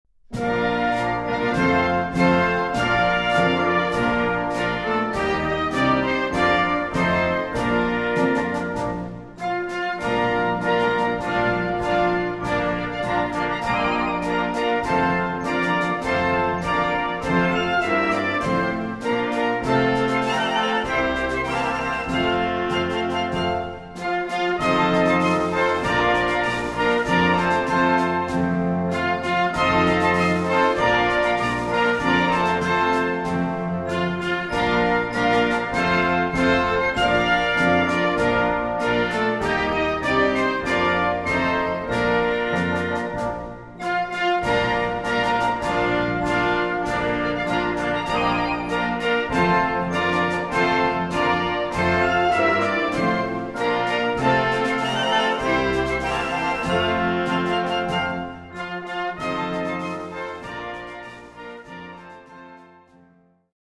Clarinette et Piano